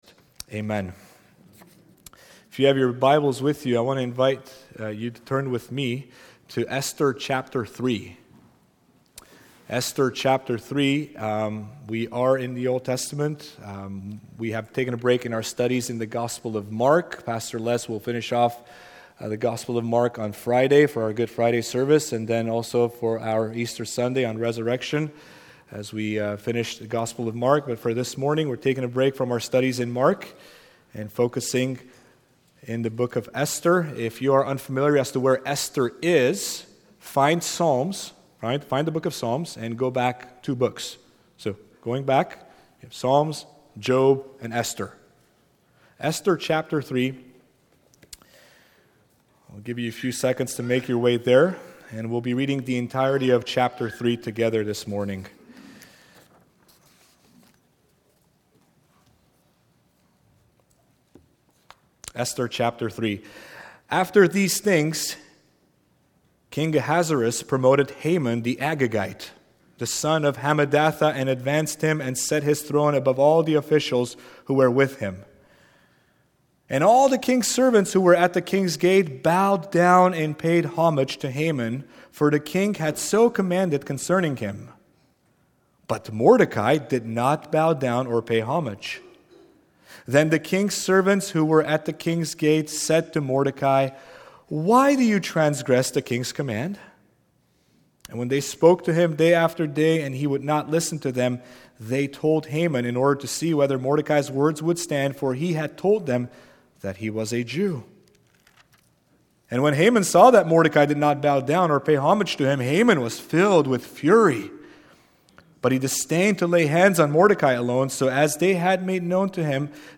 Sermon Notes:1. The Cost of Resistance (3:1-6)2. The Folly of Sin (3:7-11)3. The God of Reversals (3:12-15)